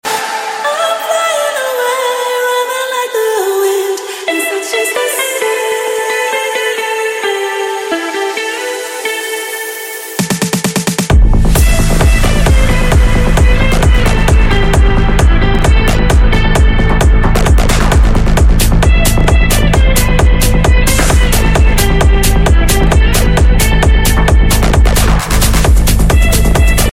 Skydiving through a cloud ☁ sound effects free download